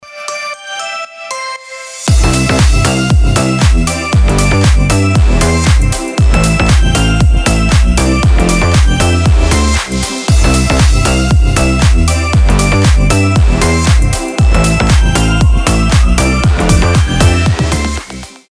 • Качество: 320, Stereo
мелодичные
без слов
колокольчики
звонкие
рождественские
slap house
Хороший ремикс новогодней рекламной песенки